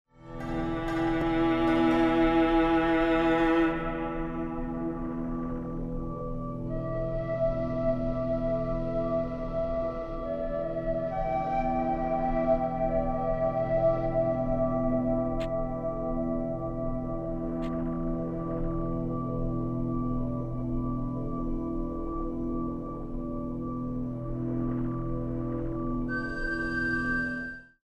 Long tracks of meditative music for stretch classes